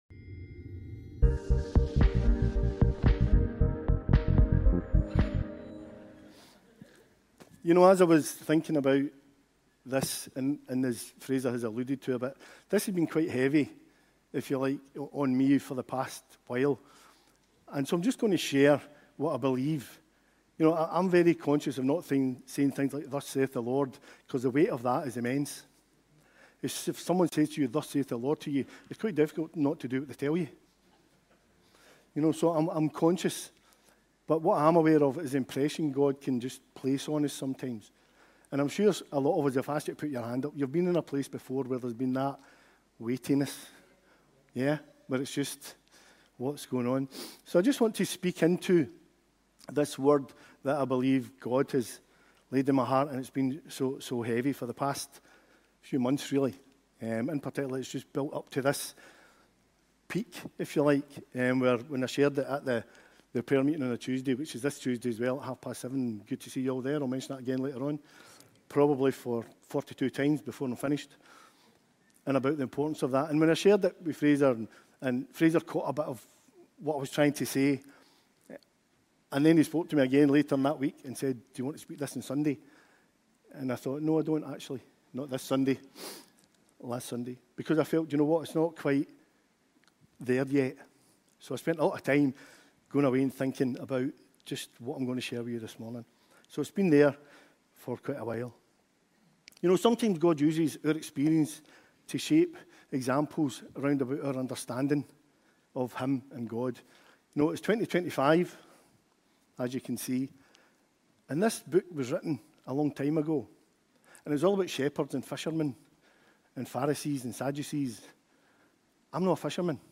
Teaching and Preaching from Glasgow Elim Church.